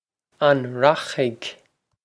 Pronunciation for how to say
On rokh-ig?
This is an approximate phonetic pronunciation of the phrase.